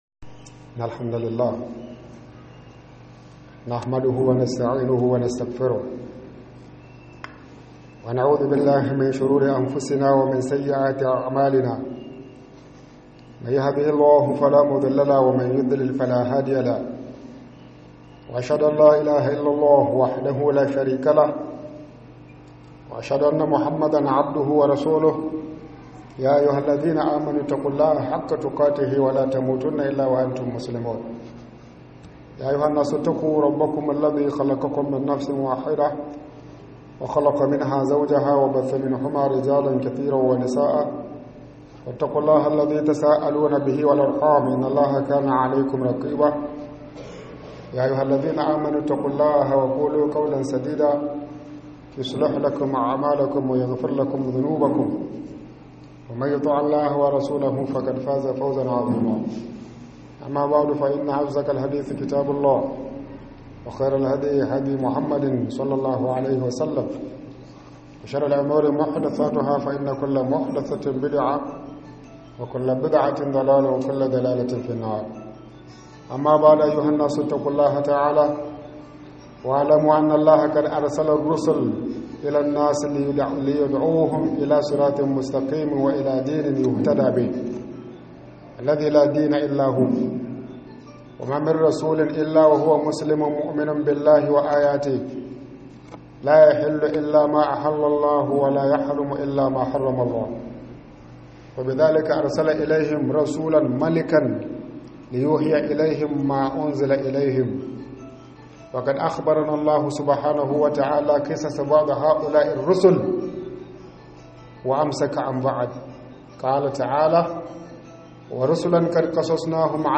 Khudbah kan KIRSIMETI